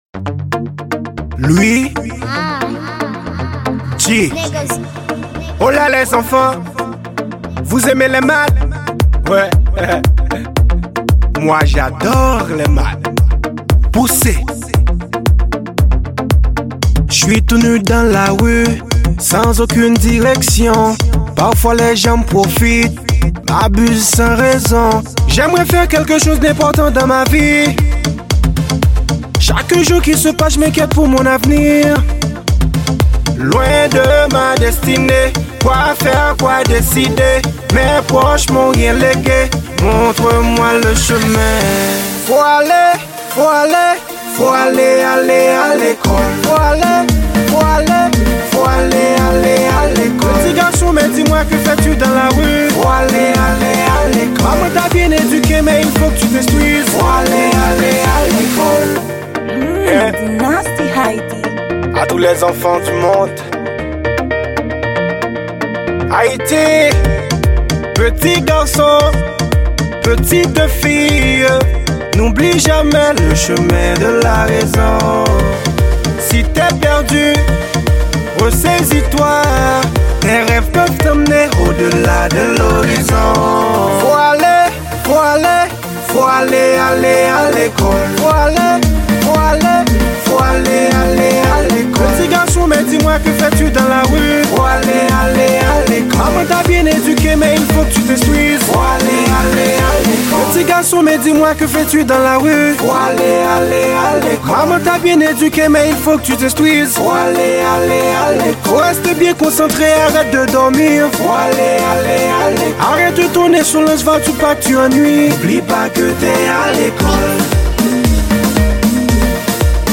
Genre: variete.